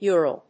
音節U・ral 発音記号・読み方
/jˈʊ(ə)rəl(米国英語)/